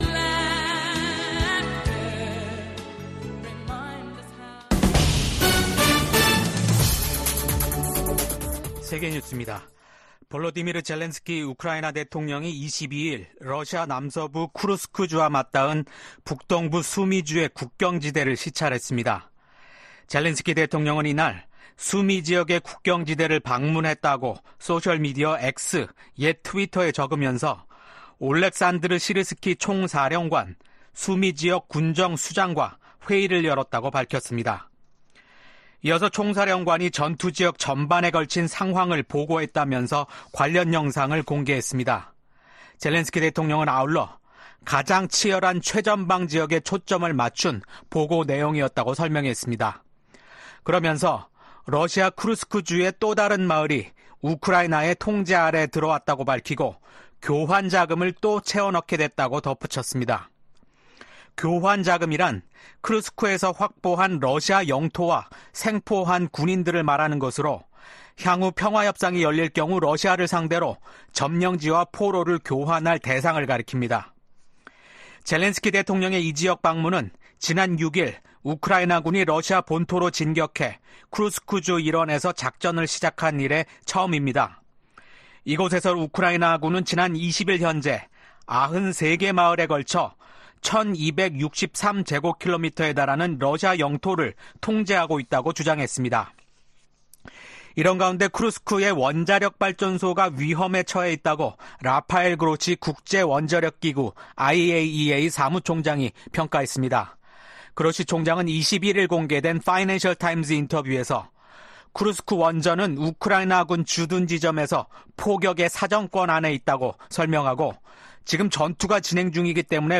VOA 한국어 아침 뉴스 프로그램 '워싱턴 뉴스 광장' 2024년 8월 23일 방송입니다. 미국 국방부는 미한 연합훈련인 을지프리덤실드 연습이 방어적 성격이란 점을 분명히 하며 ‘침략 전쟁 연습’이란 북한의 주장을 일축했습니다.